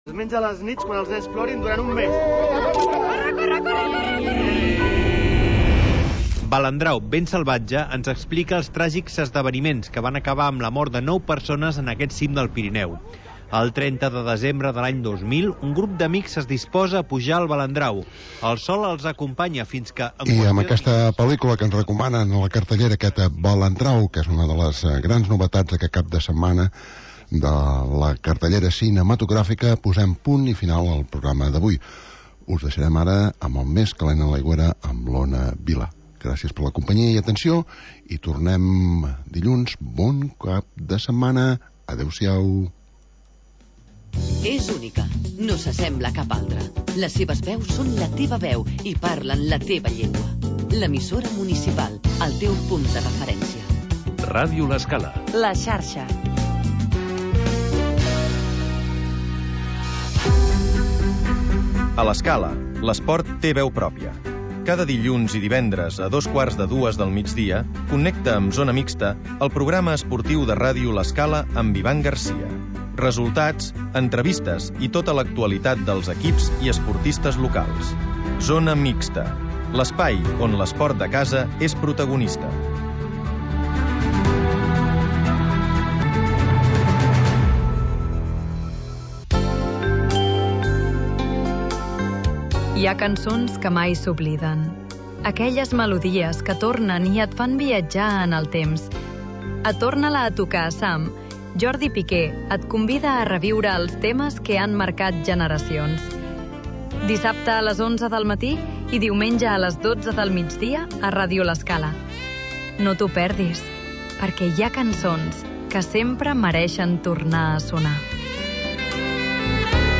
Magazín d'entreteniment per passar el migdia